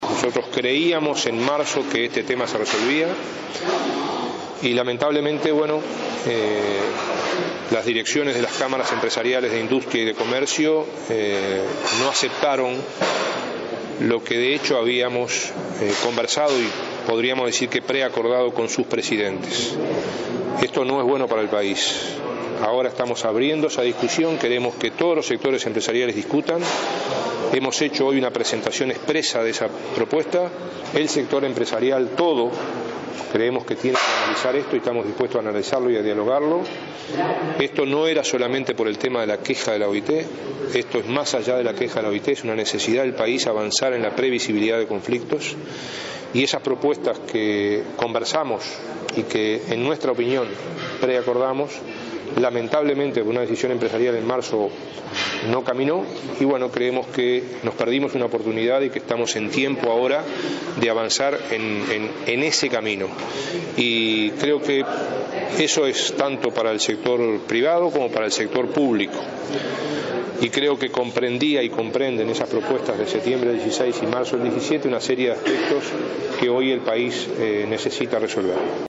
“Uruguay debe avanzar en la previsibilidad de conflictos en una época donde los hay de trabajadores y de empresarios”, señaló el ministro de Trabajo, Ernesto Murro, al lamentar que en marzo las cámaras empresariales no hayan aceptado un preacuerdo al respecto. En su disertación en los desayunos de trabajo de la revista Somos Uruguay, llamó a la responsabilidad a todas las partes.